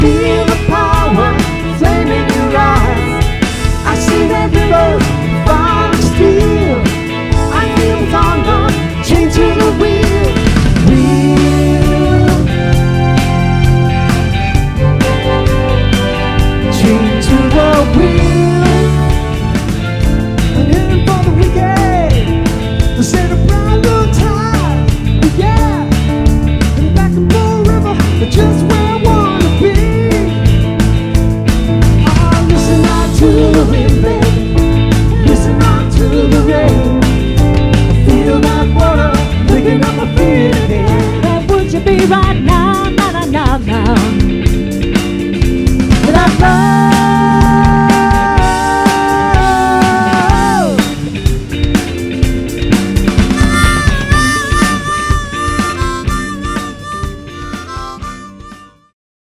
classic rock favourites guaranteed to light…